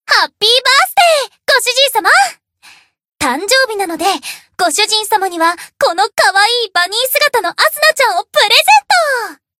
贡献 ） 分类:蔚蓝档案语音 协议:Copyright 您不可以覆盖此文件。
BA_V_Asuna_Bunny_Season_Birthday_Player.ogg